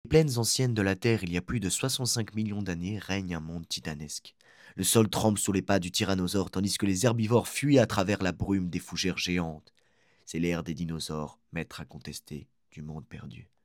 Voix documentaire